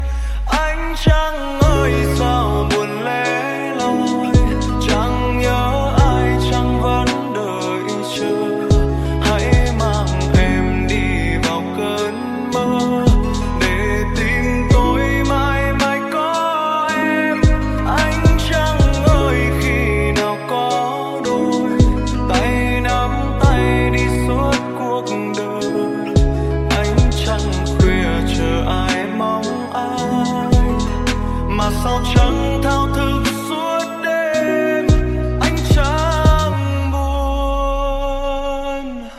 Lofi